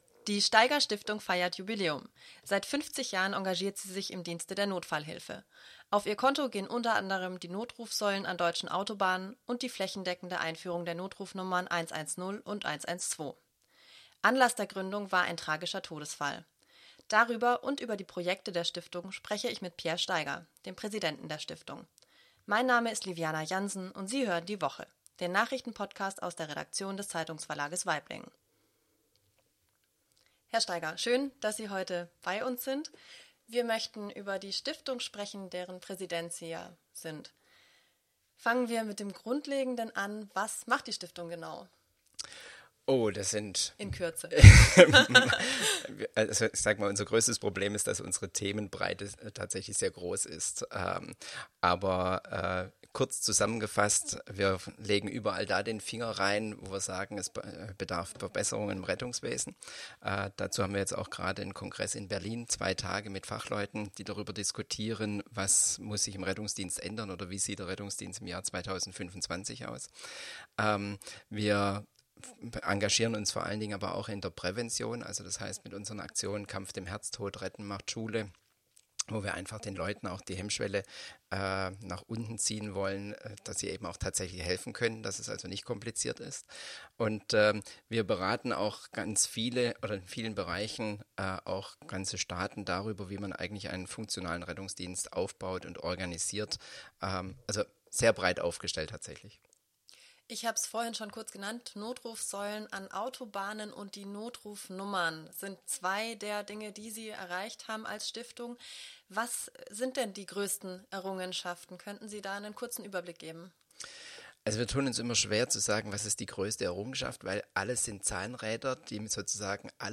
Nachrichten-Podcast